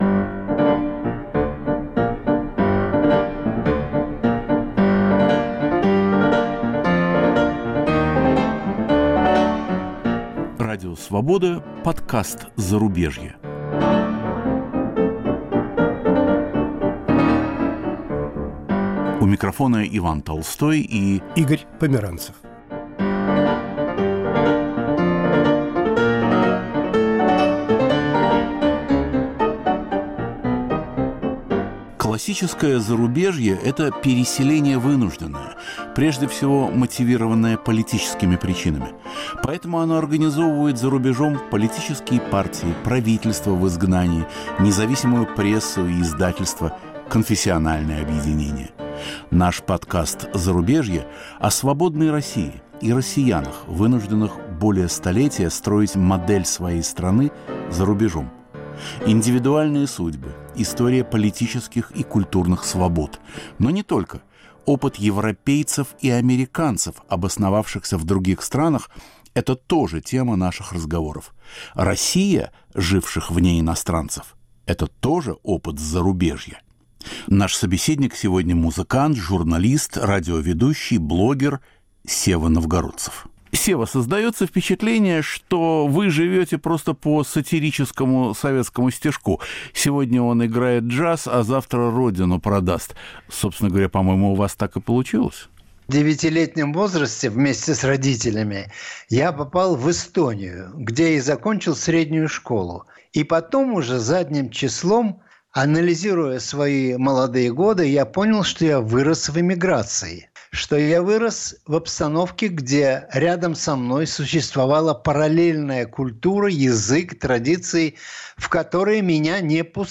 Подкаст "Зарубежье". Наш собеседник сегодня – музыкант, журналист, радиоведущий, блогер Сева Новгородцев.